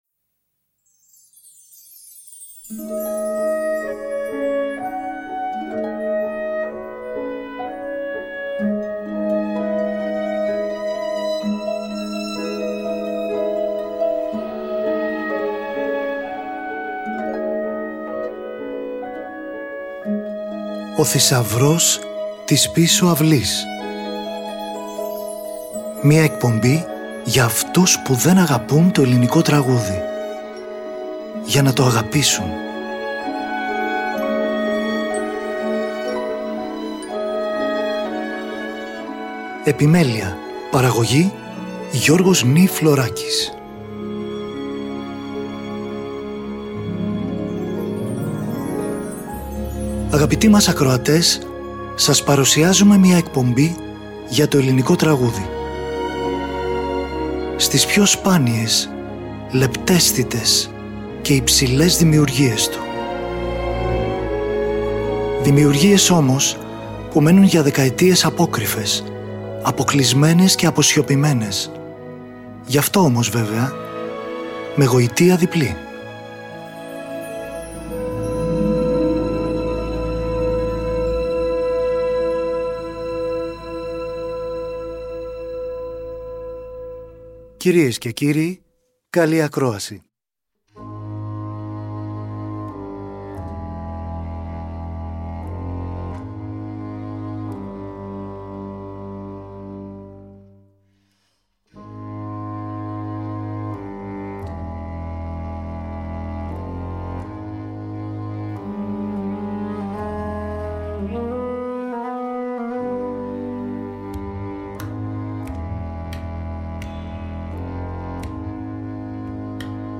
καβάλ
ούτι
σαντούρι
κρουστά
άρπα
άλτο φλάουτο
όμποε, αγγλικό κόρνο
μπάσο κλαρινέτο
φαγκότο, κόντρα φαγκότο